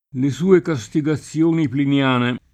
castigazione [kaStigaZZL1ne] s. f. — raro latinismo lett., attestato, piuttosto che nel sign. di «punizione (di persone)», in quello di «emendazione filologica (di testi)»: le sue castigazioni pliniane [
le S2e kaStigaZZL1ni plinL#ne] (Bembo)